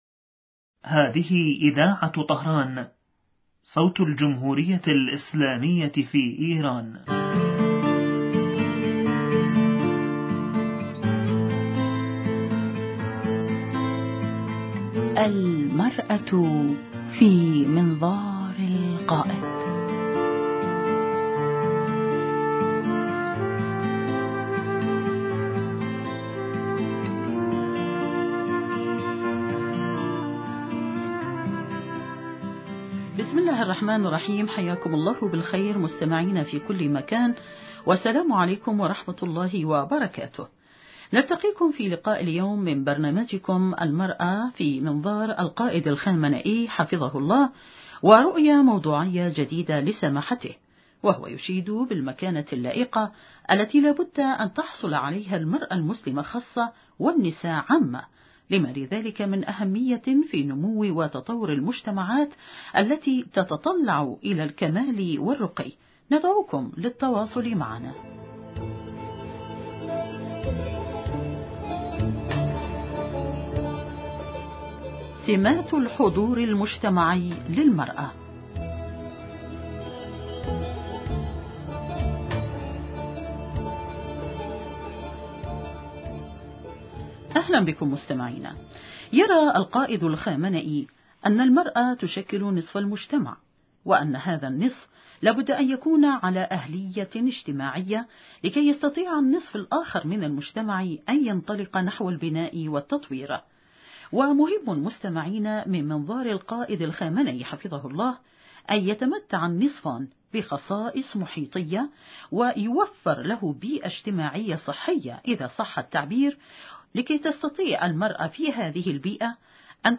ضيفة البرنامج عبر الهاتف